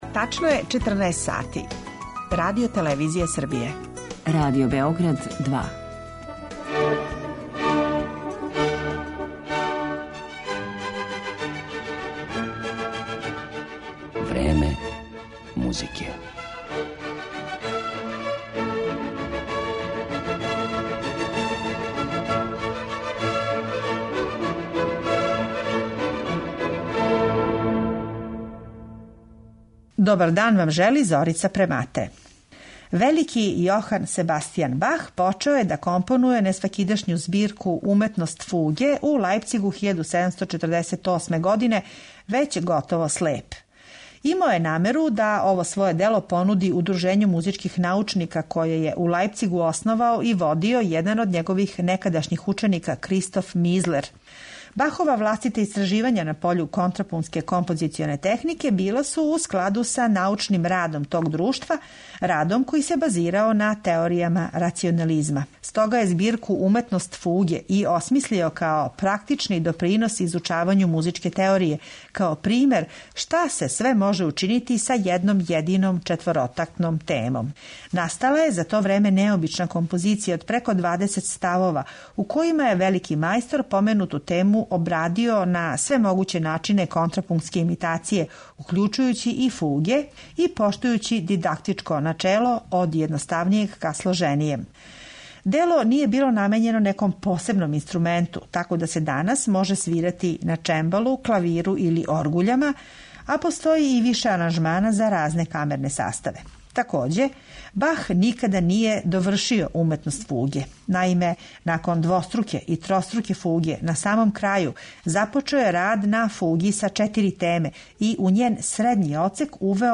углавном полифоног стила и за оргуље